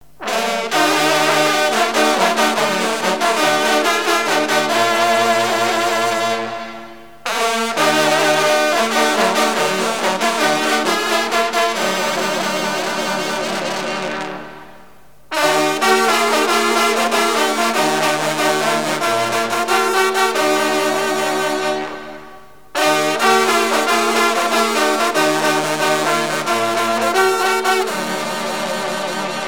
fanfare
rencontre de sonneurs de trompe
Pièce musicale éditée